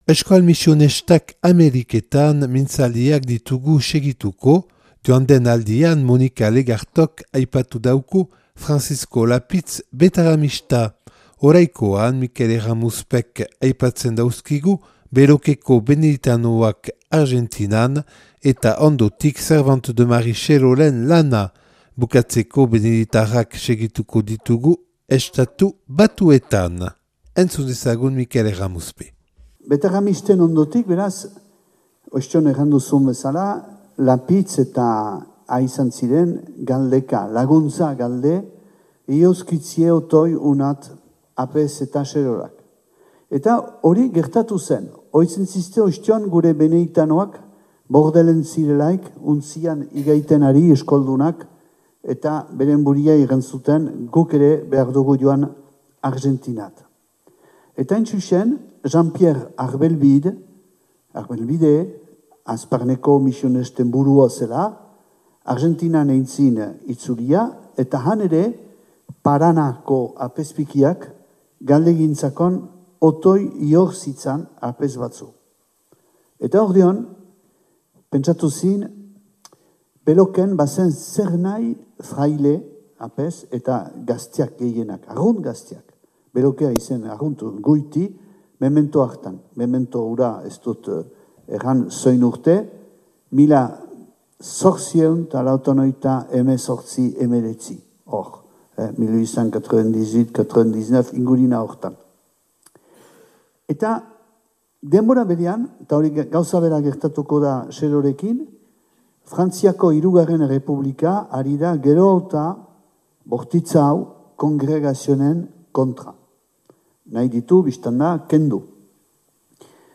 (2023. urriaren 14an grabatua Donapaleuko Bidea gunean).